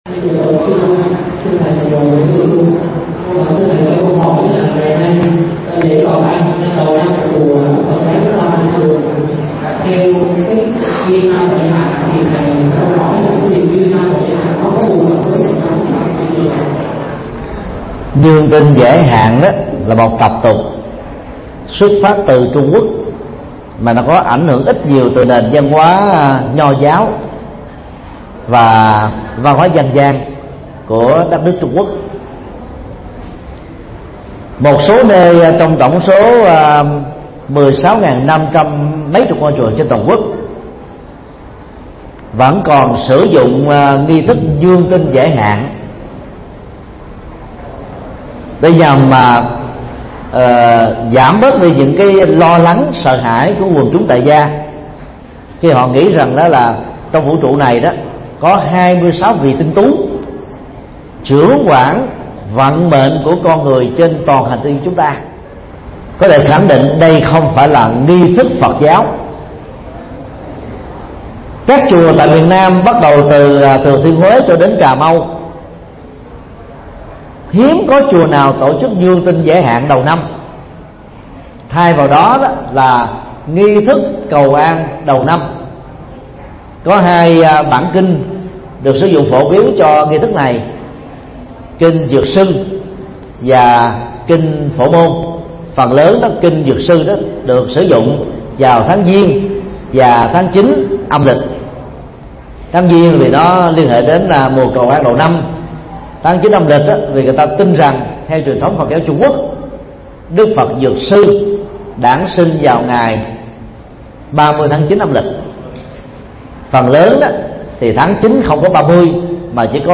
Vấn đáp: Giải thích văn hóa cúng sao giải hạn – Thầy Thích Nhật Từ